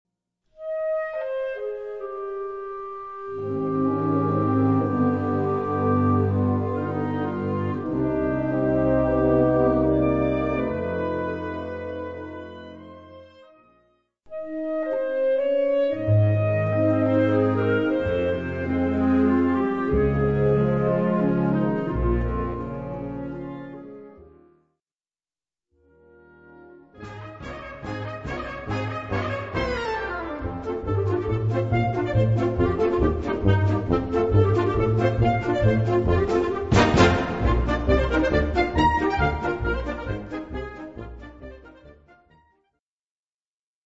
Unterkategorie Konzertmusik
Besetzung Ha (Blasorchester)